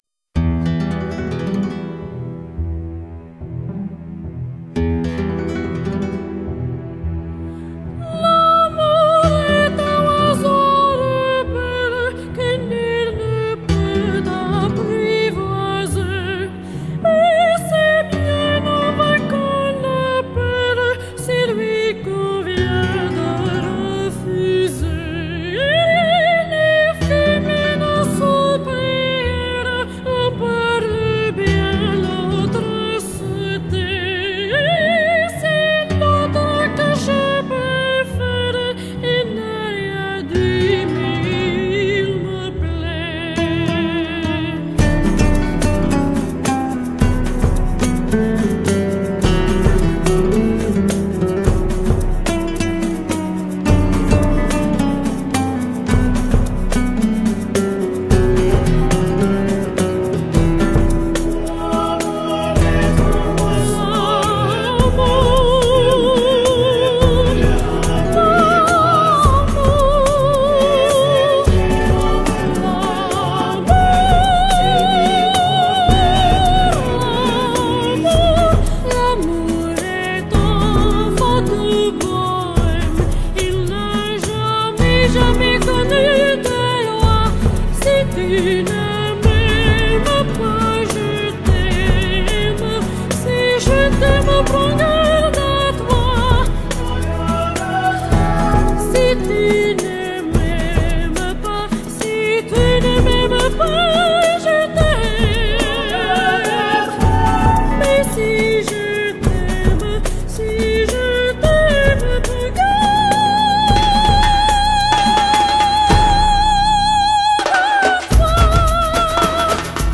性别：女
晕的我迷醉在这优秀的声线中